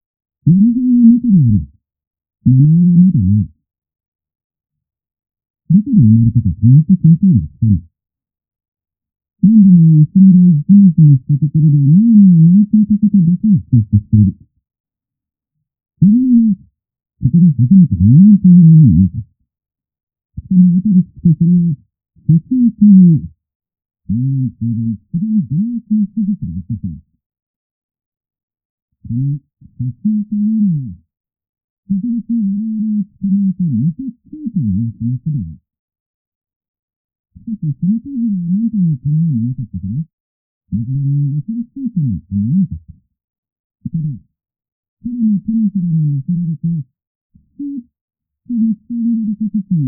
テレビの音には、低音〜高音の音が含まれます。
・テレビの音は、設置前の音 23秒 → 設置後の音 23秒
※壁越しの音声のため、音が曇って歪んでいます。